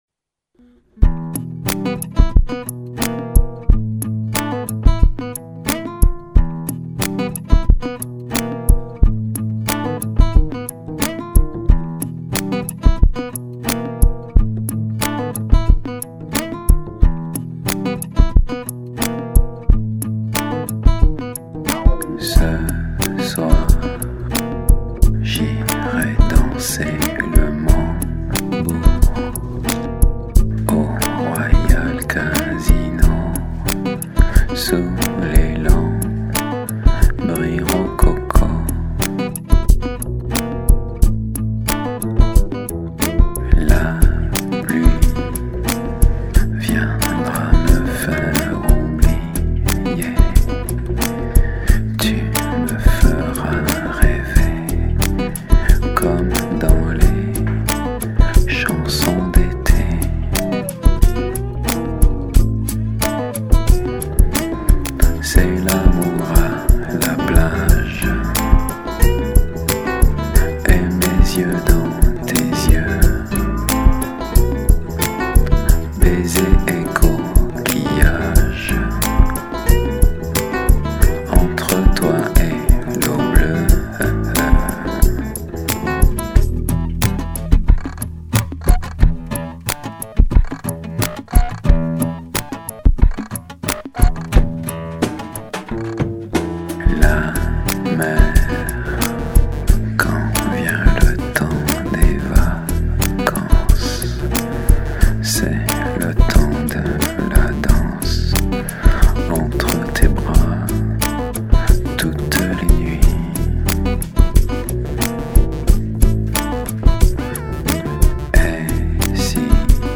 Contrebasse
Guitare